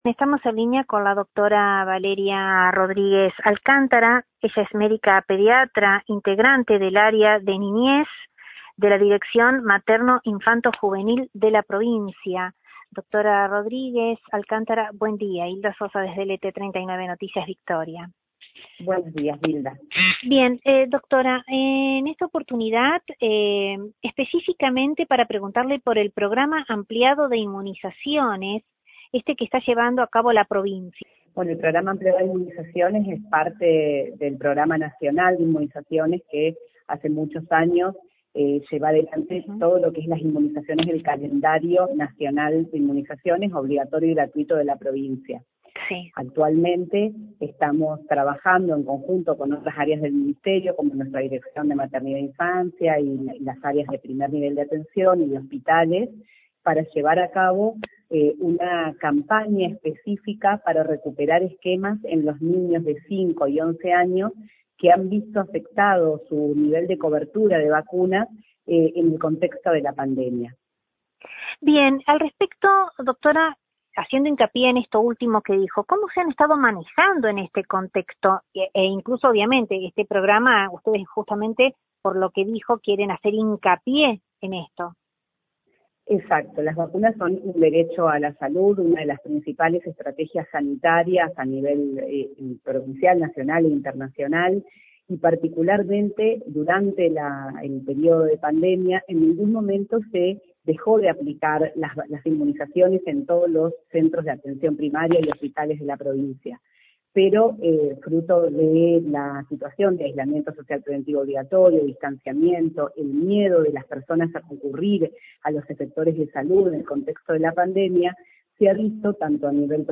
Desde LT39 NOTICIAS, dialogamos al respecto